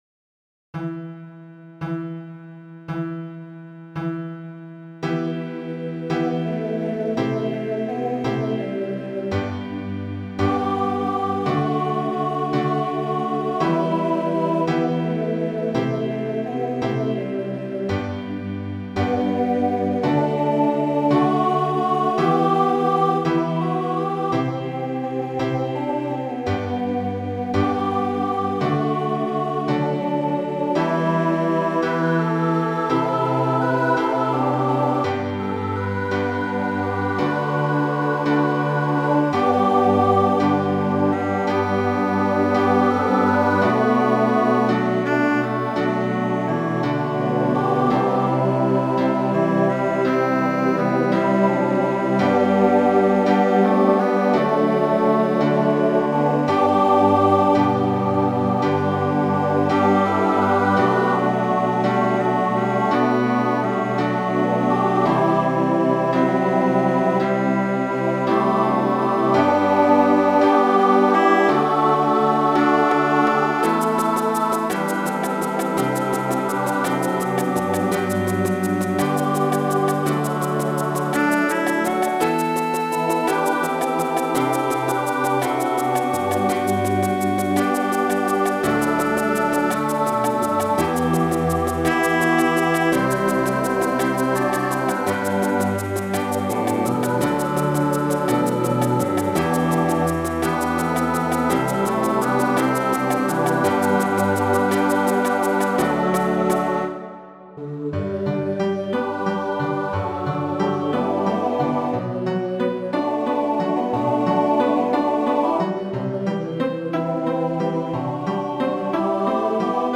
SATB Instrumental combo
Pop/Dance
Ballad